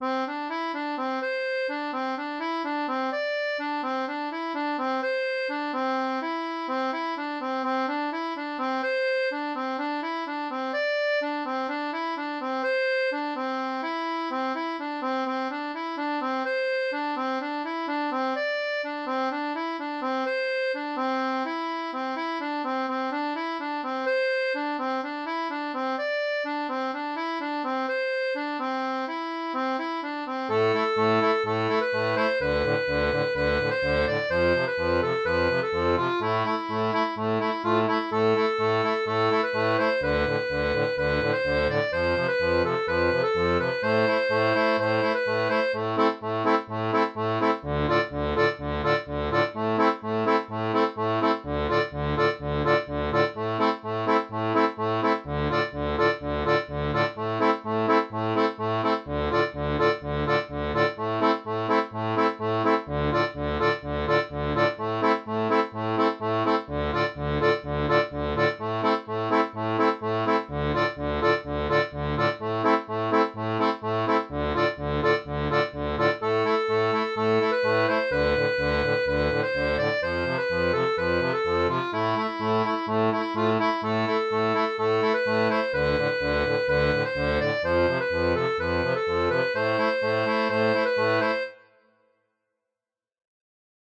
Reggae Ska italien
Reggae